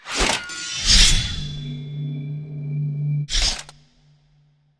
tank_skill_shieldbuff.wav